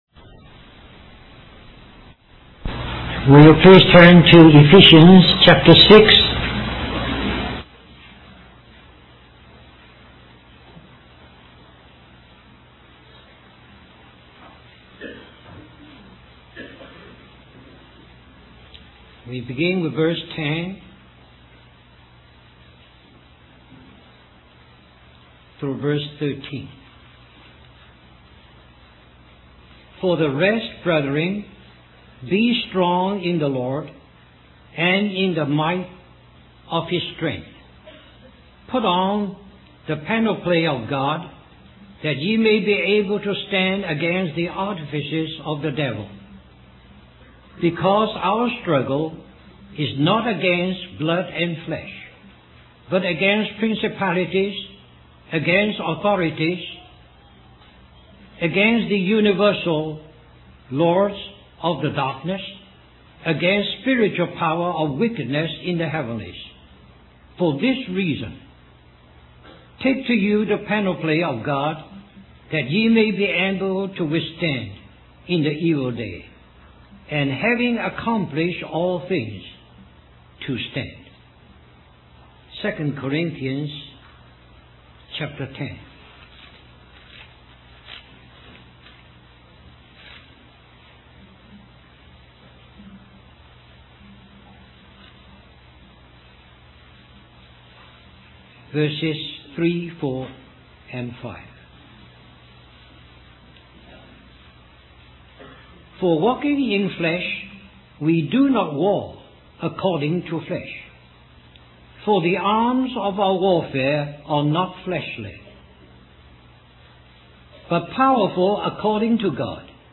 A collection of Christ focused messages published by the Christian Testimony Ministry in Richmond, VA.
2001 Harvey Cedars Conference Stream or download mp3 Summary This message is also printed in booklet form under the title